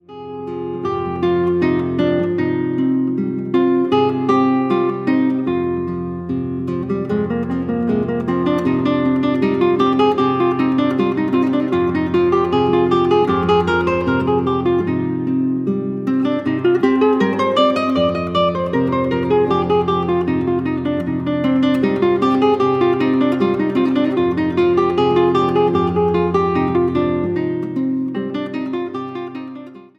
classical guitar
Instrumentaal | Gitaar